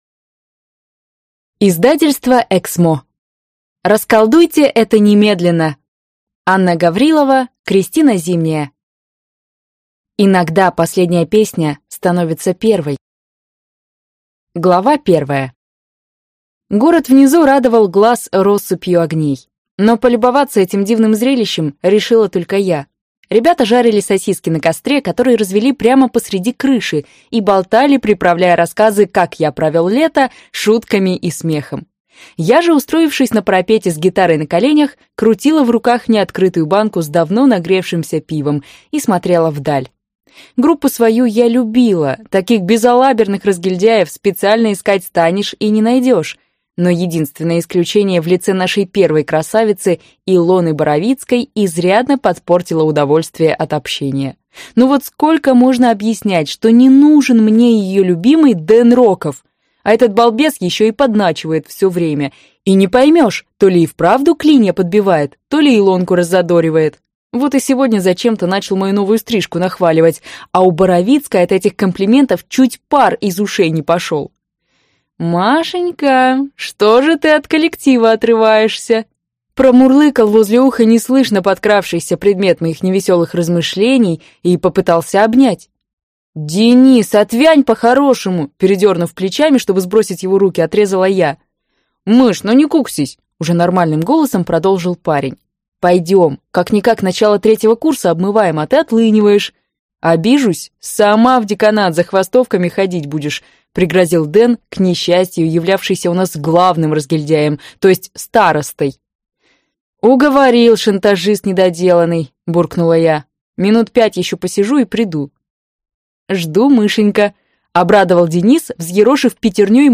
Аудиокнига Расколдуйте это немедленно!
Прослушать фрагмент аудиокниги Расколдуйте это немедленно!